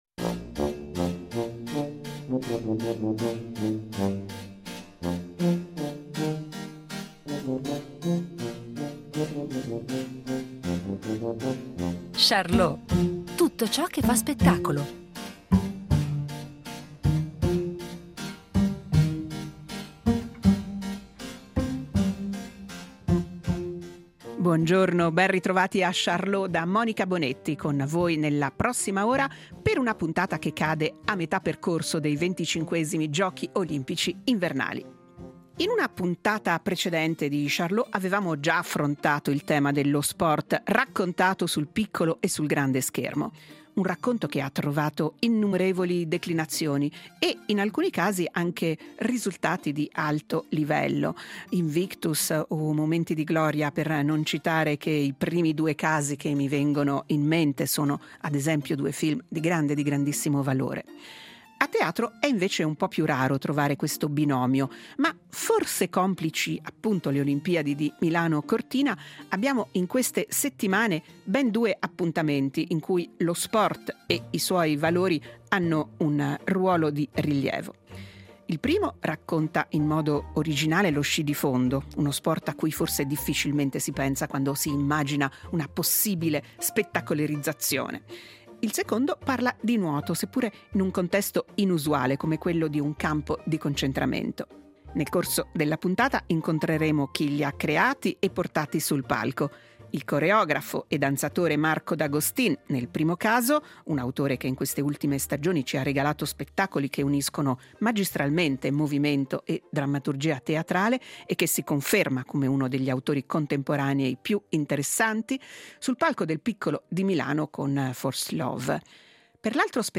Ospite della puntata il coreografo